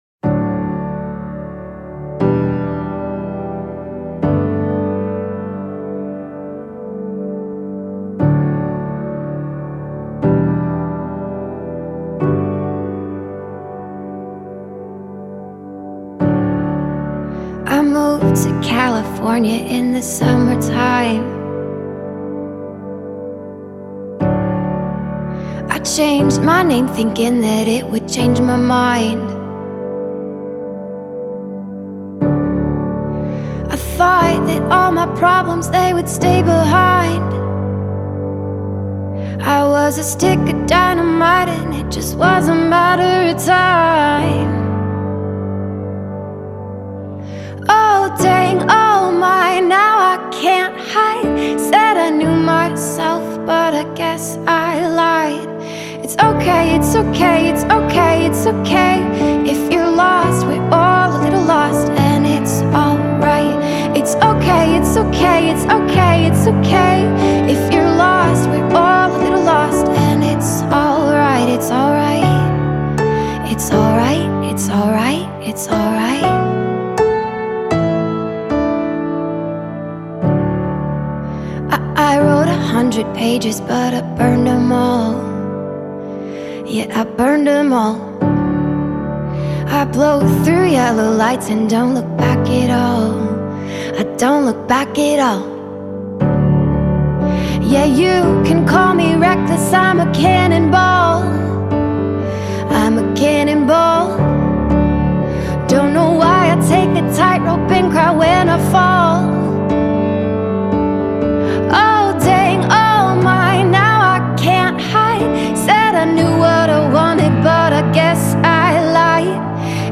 February 19, 2025 Publisher 01 Gospel 0